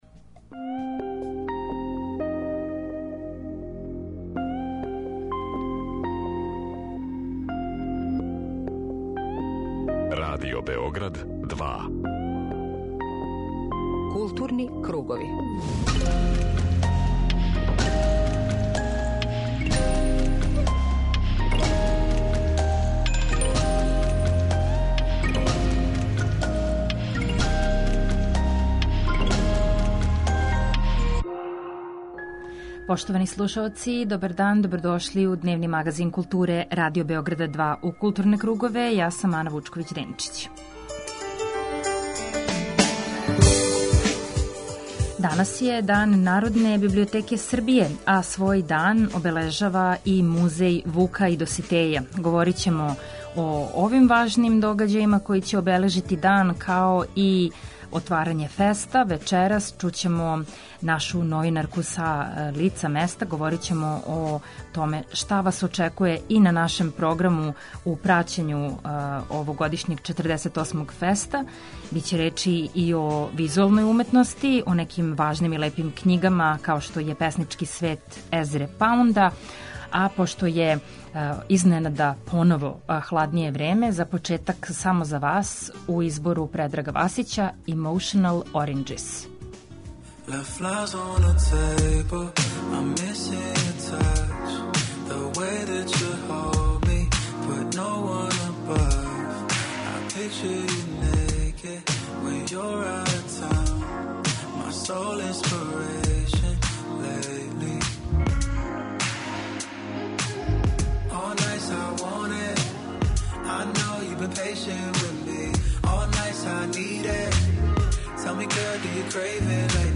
Вечерас почиње 48. издање Међународног филмског фестивала ФЕСТ. Чућемо укључење са лица места и најавити оно што не треба пропустити међу бројним остварењима.